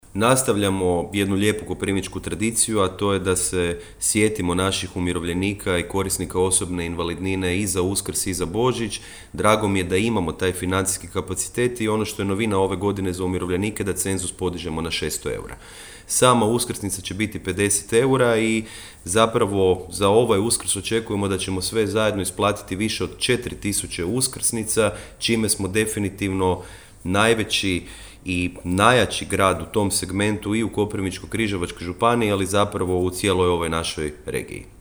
– rekao je gradonačelnik Jakšić.